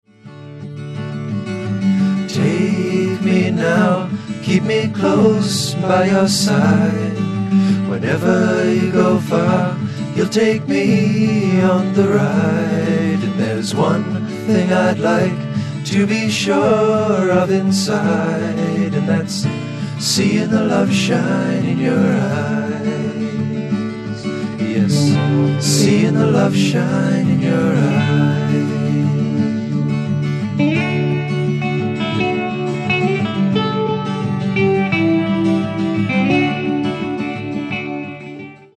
Progressive Rock
デビュー作とは思えないほど、ファンキーかつハードなグルーヴを生み出している。